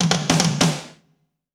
British SKA REGGAE FILL - 09.wav